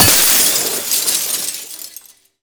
glass_smashable_large_break_04.wav